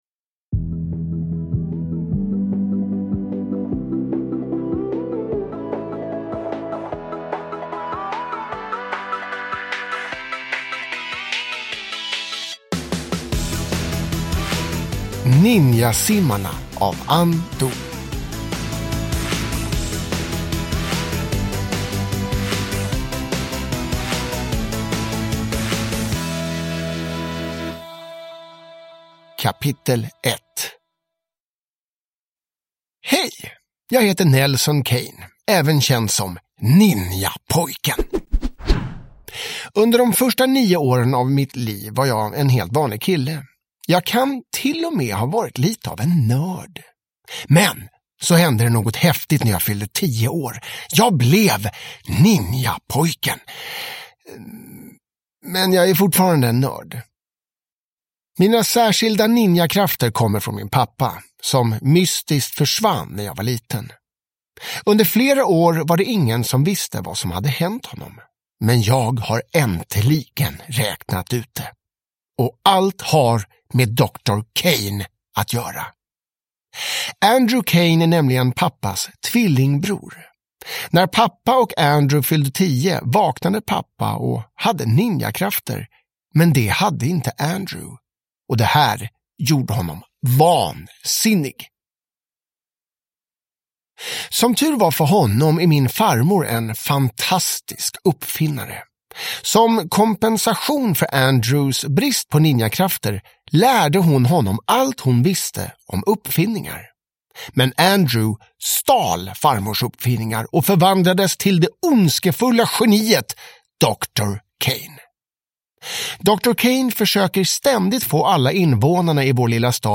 Ninjasimmarna – Ljudbok